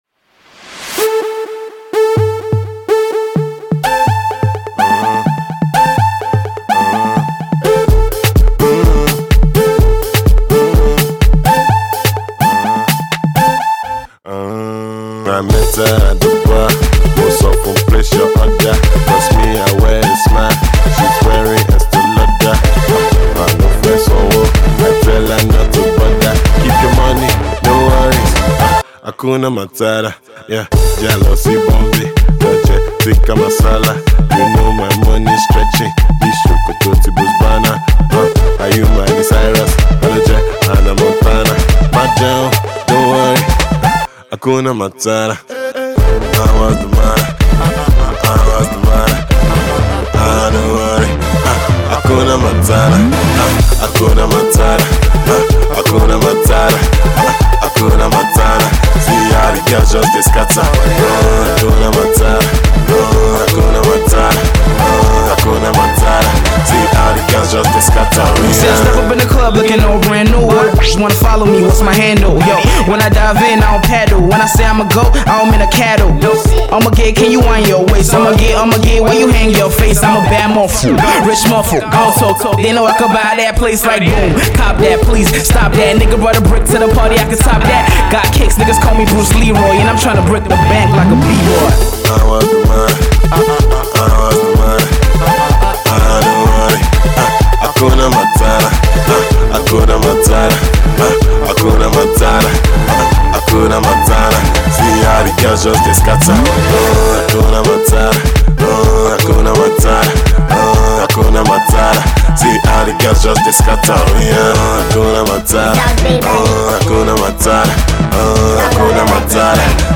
to the Afro-Dance production from